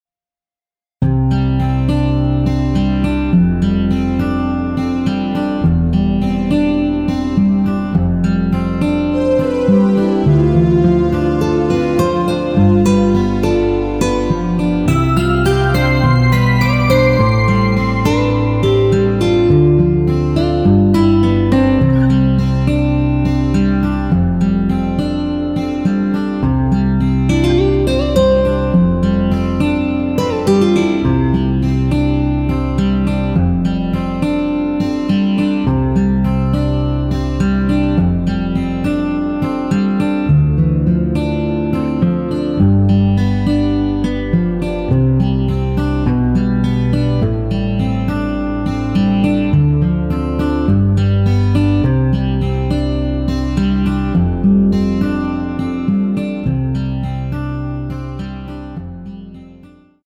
앞부분30초, 뒷부분30초씩 편집해서 올려 드리고 있습니다.
곡명 옆 (-1)은 반음 내림, (+1)은 반음 올림 입니다.